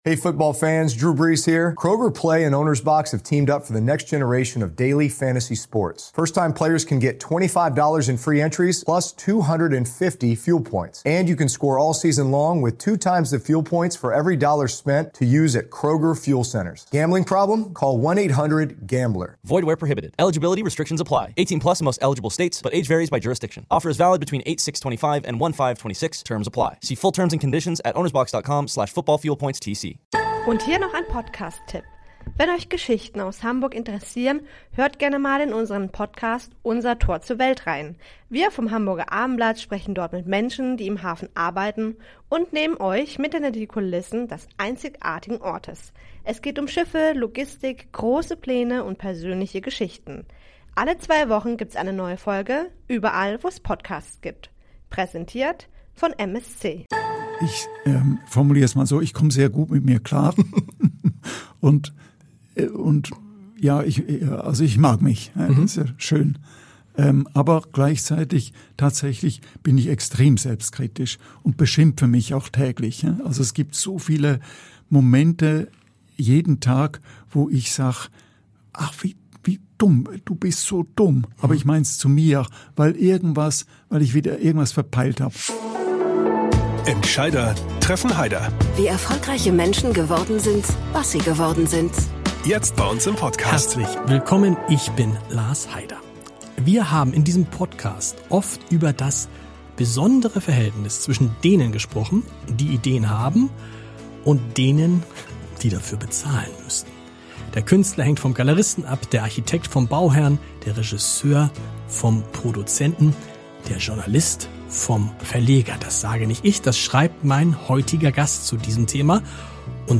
Hören Sie jetzt ein Interview über die Wahrheit, die Verantwortung und das, was wirklich "Am Ende" zählt.